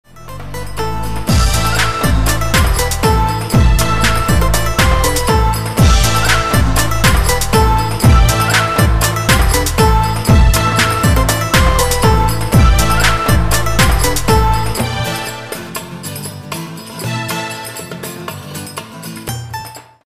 Folk music- instrumental music